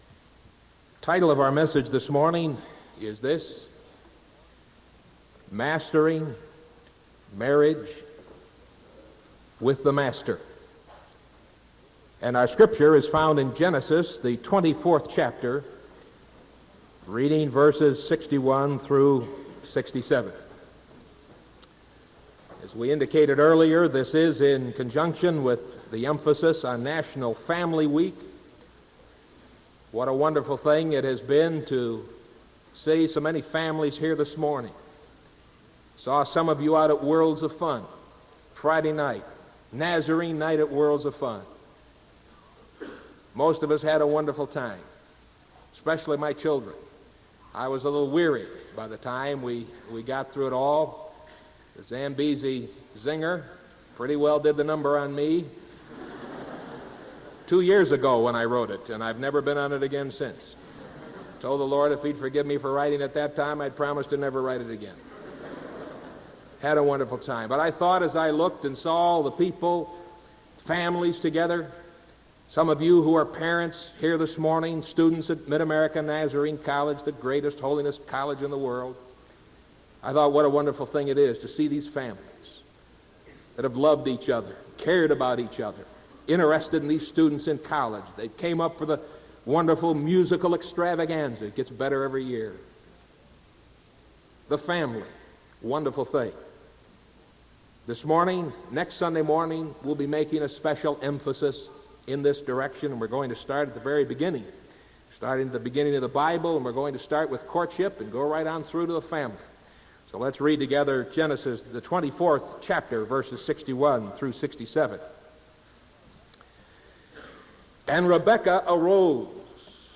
Sermon May 4th 1975 AM